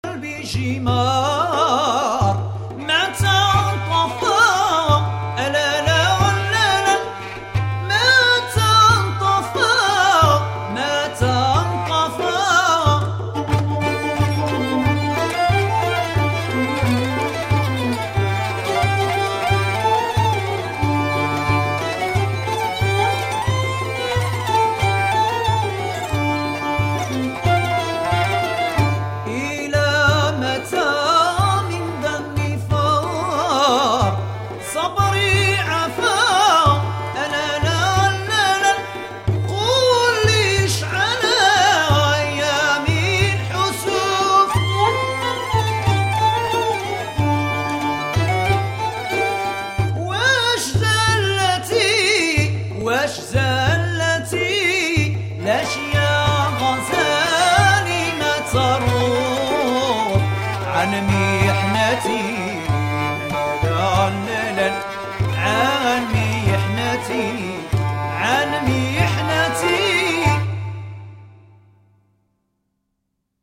Musiques d´al Andalus